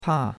pa4.mp3